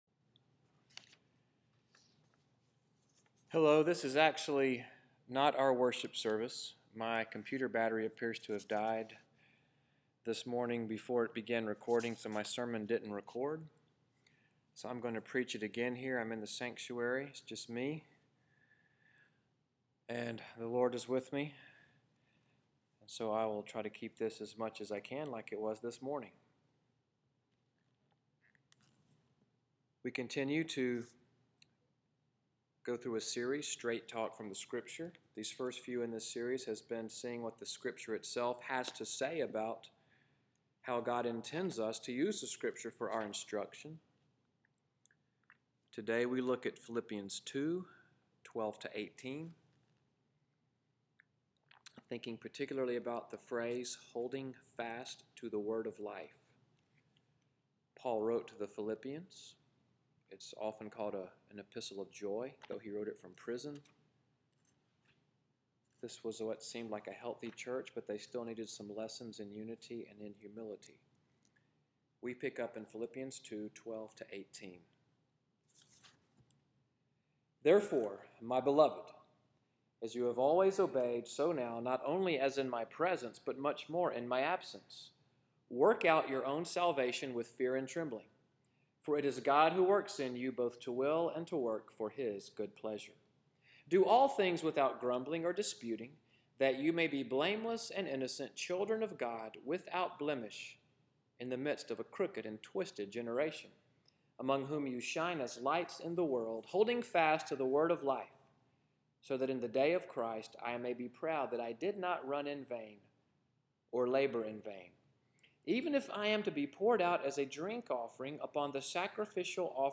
Below is indeed today’s sermon, but not from today’s worship service.
I returned to the sanctuary after lunch and preached it again.